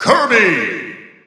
The announcer saying Kirby's name in English and Japanese releases of Super Smash Bros. 4 and Super Smash Bros. Ultimate.
Kirby_English_Announcer_SSB4-SSBU.wav